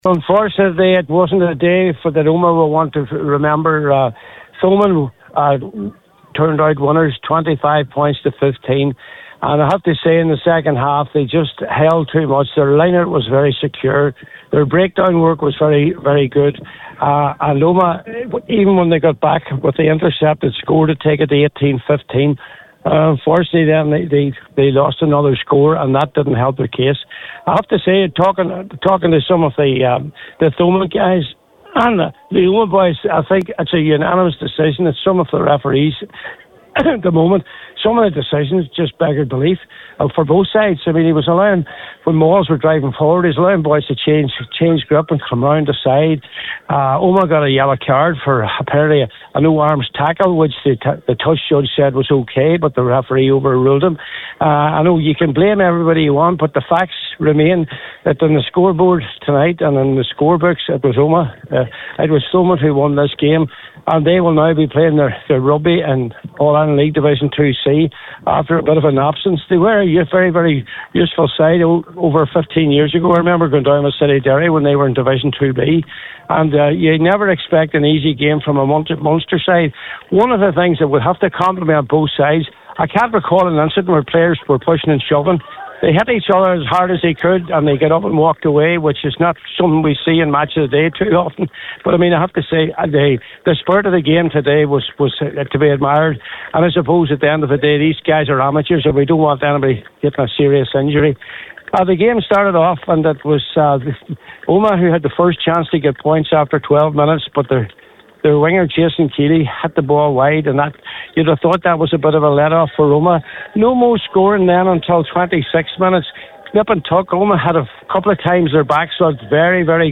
was there for Highland Radio Saturday Sport…